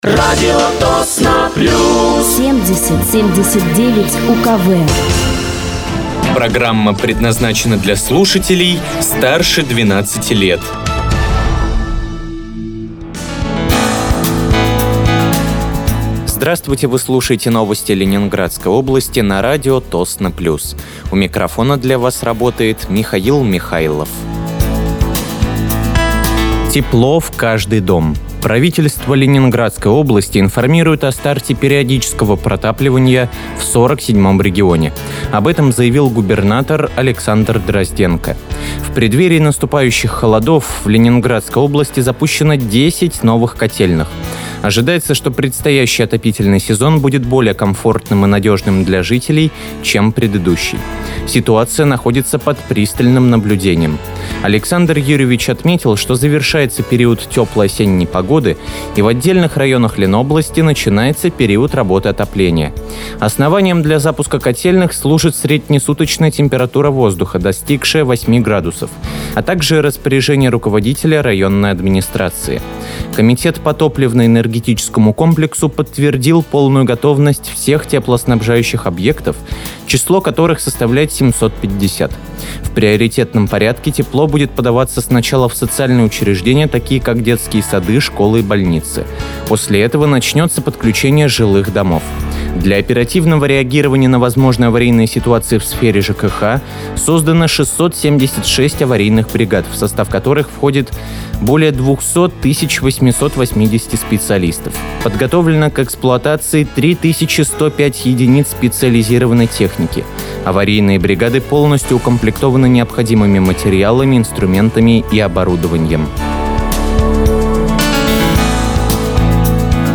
Выпуск новостей Ленинградской области от 26.09.2025
Вы слушаете новости Ленинградской области от 26.09.2025 на радиоканале «Радио Тосно плюс».